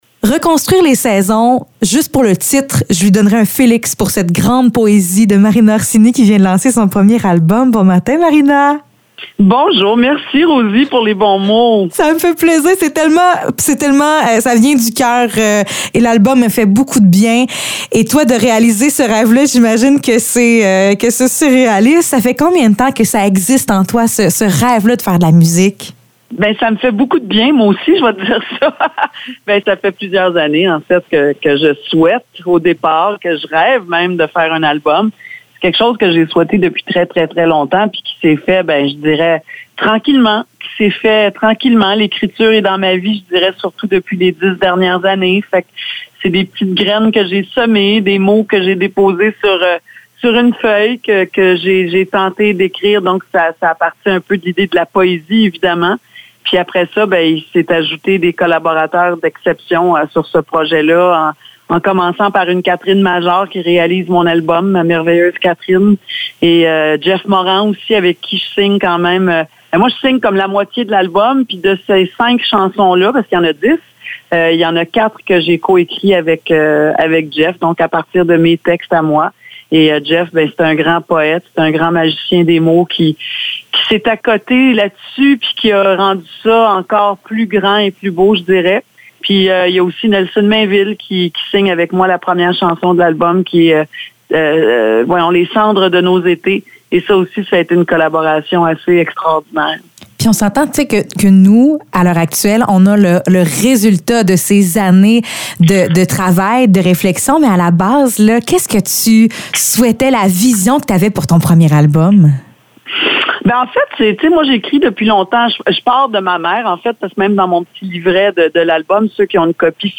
Entrevue avec Marina Orsini